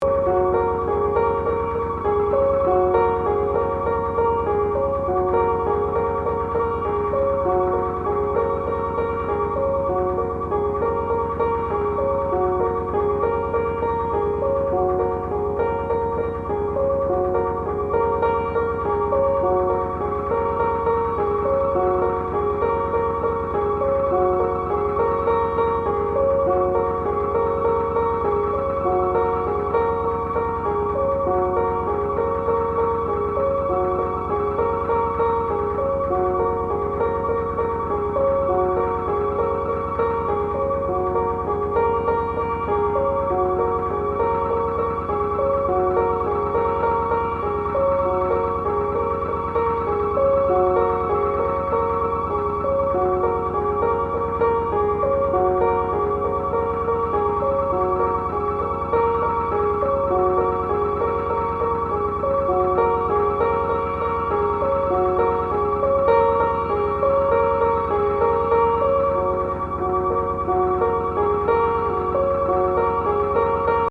piano, synth, voice and tapes. As delicate as the LP.